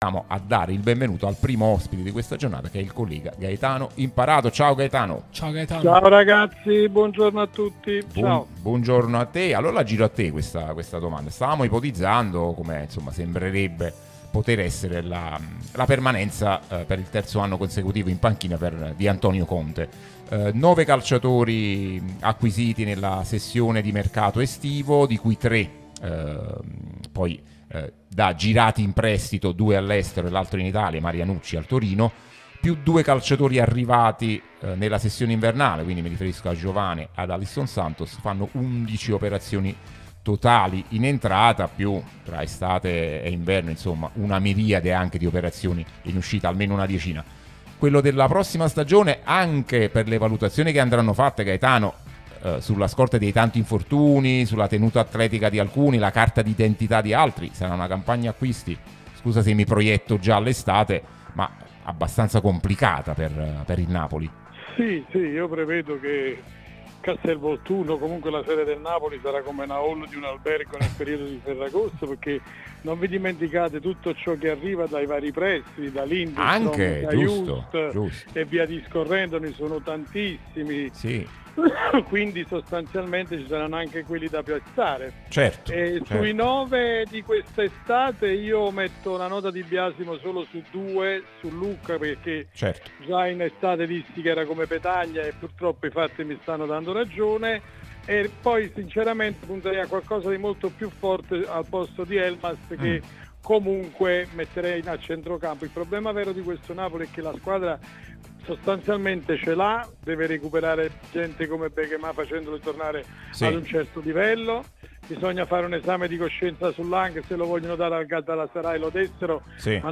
trasmissione sulla nostra Radio Tutto Napoli